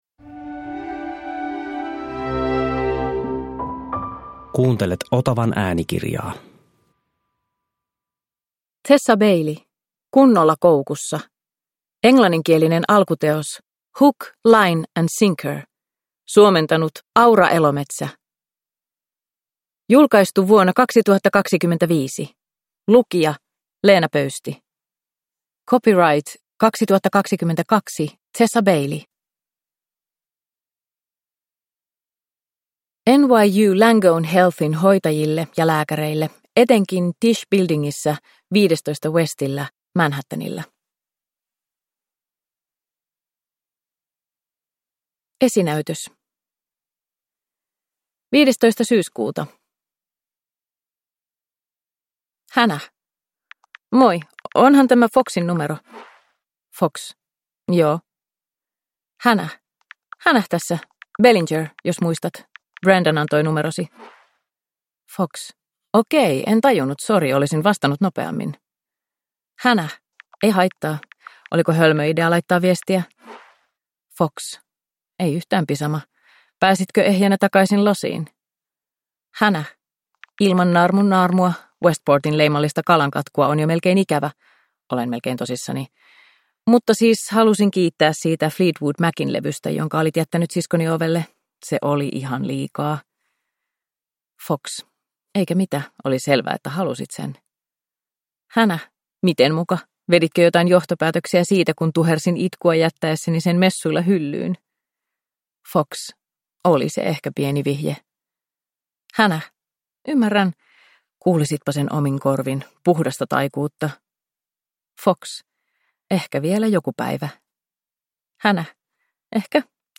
Kunnolla koukussa (ljudbok) av Tessa Bailey